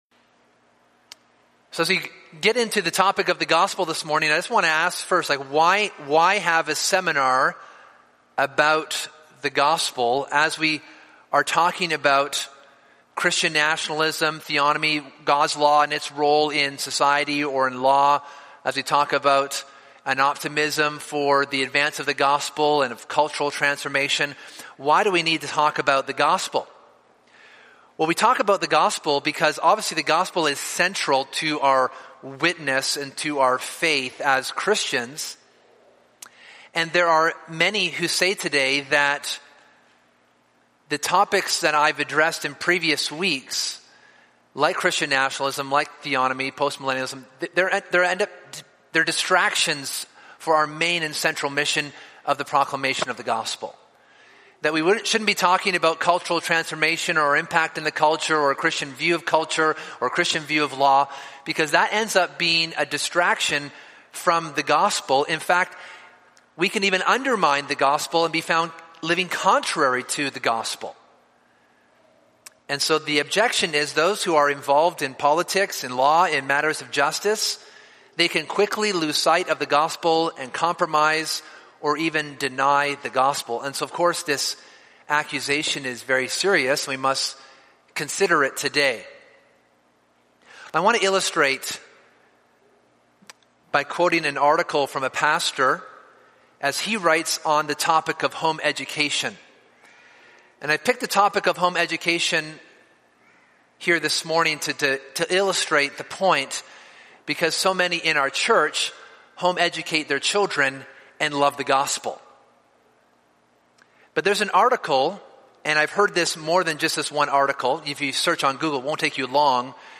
This is part four of a seminar series on Christian Nationalism, Theonomy, Postmillenialism, and the Gospel.